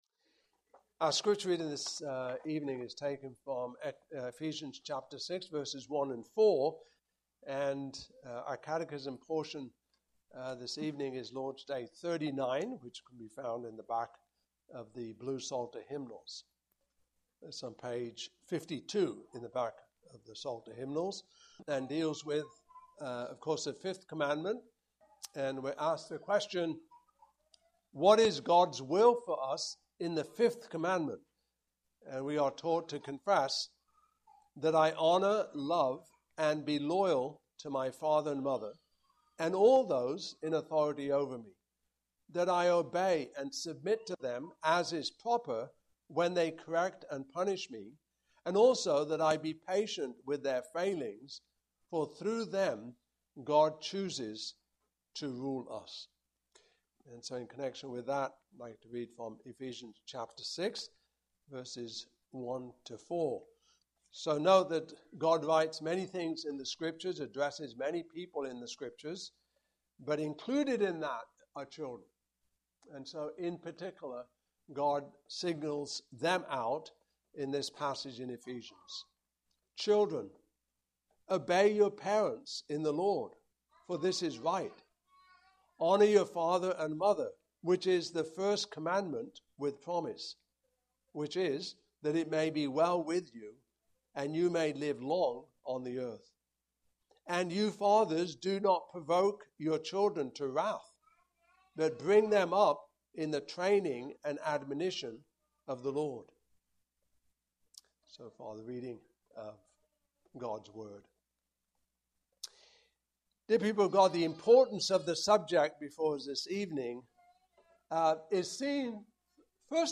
Passage: Ephesians 6:1-4, Exodus 20:12 Service Type: Evening Service